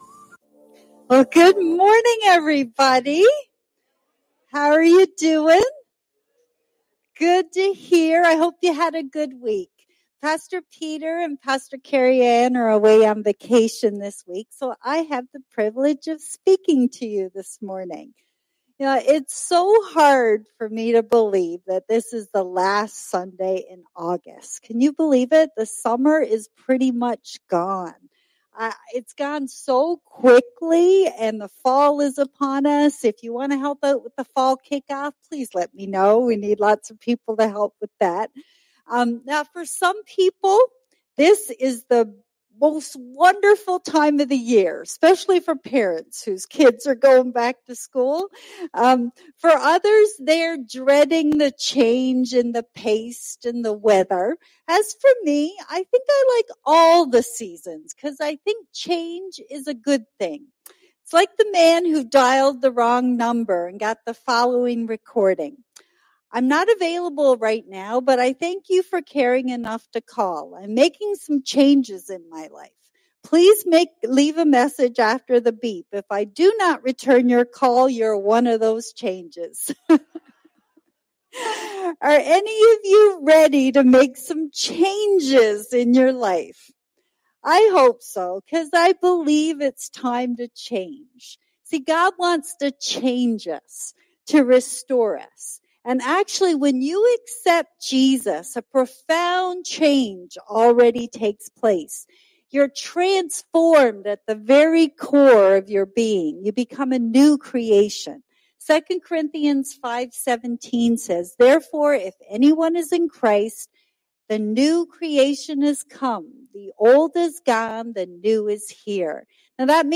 Sermons | Warden Full Gospel Assembly